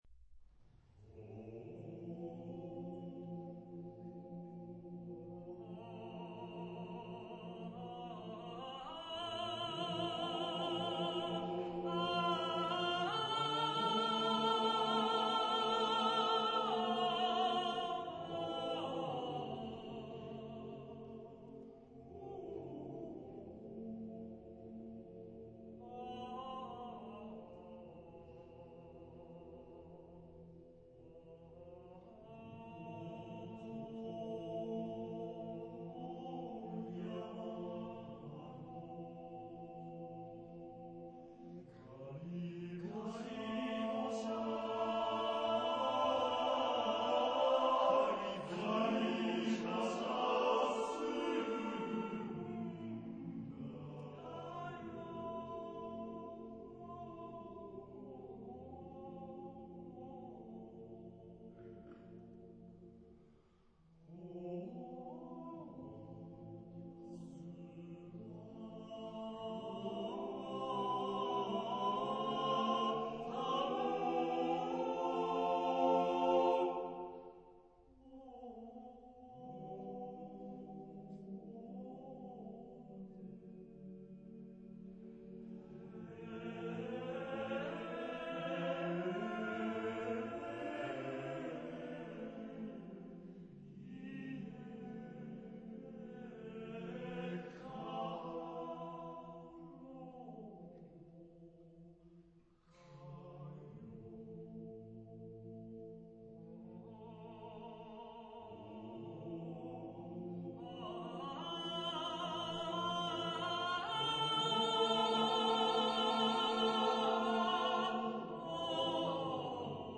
Type de matériel : Partition choeur seul
Genre-Style-Forme : Folklore
Type de choeur : TTBarB  (4 voix égales d'hommes )
Solistes : Tenor (1)  (1 soliste(s))